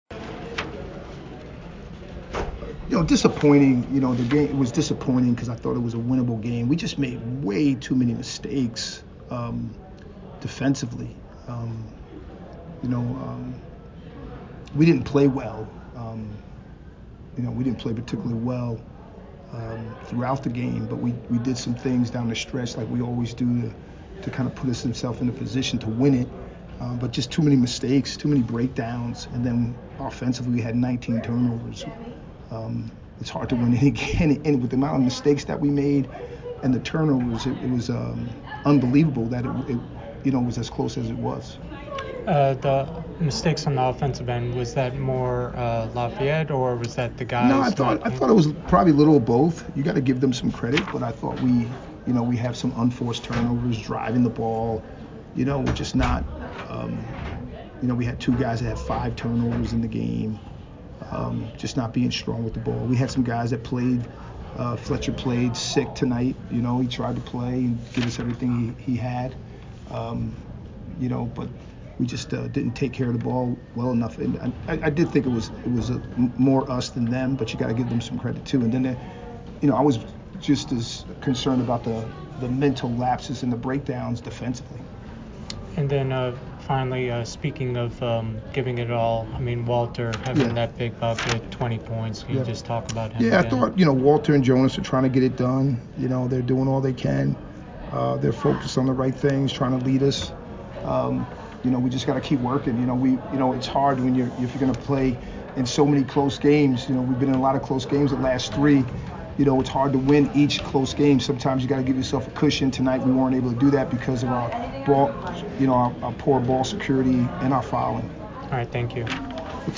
Men's Basketball / Lafayette Postgame Interview